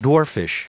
Prononciation du mot dwarfish en anglais (fichier audio)